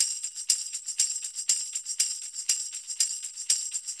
Pandereta_ ST 120_4.wav